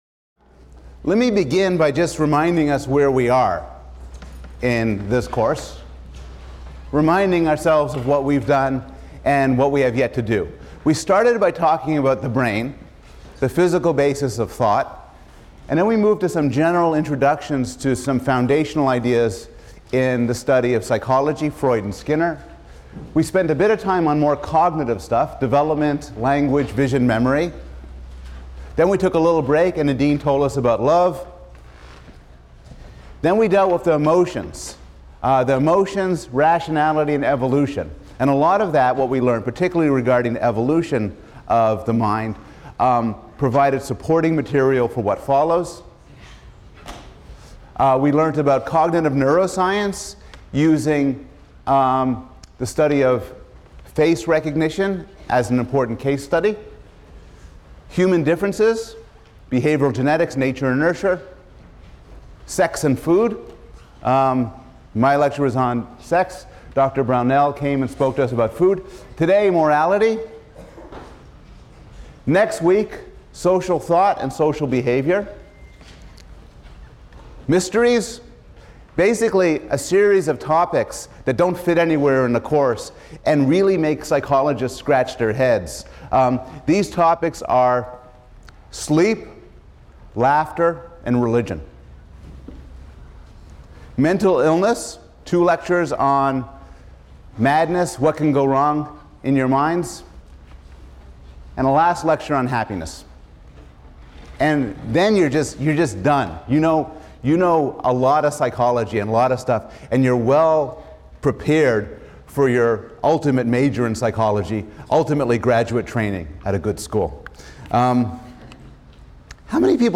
PSYC 110 - Lecture 15 - A Person in the World of People: Morality | Open Yale Courses